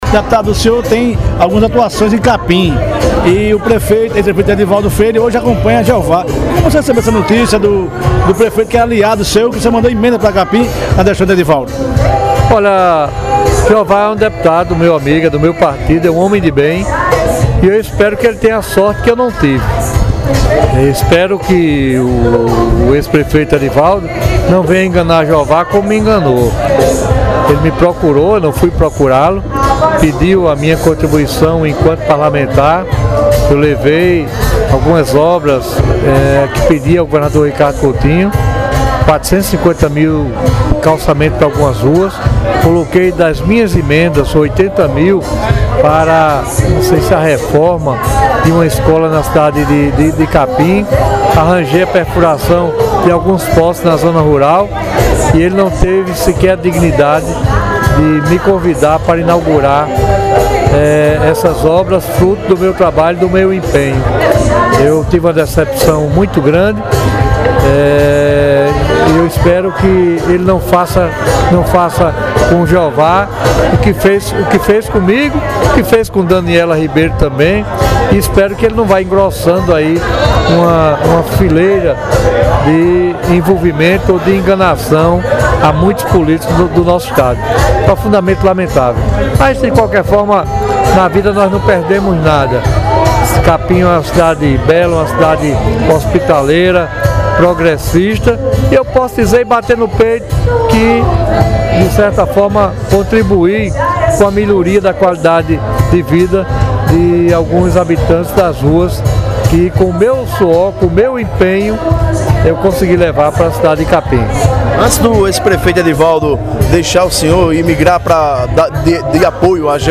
Deputado concedeu entrevista ao PBVale e a Capim Fm.
Durante evento de um Portal de Notícias na cidade de Marí, na noite do último sábado (11), o parlamentar revelou que o ex-prefeito é um verdadeiro “cara de pau”.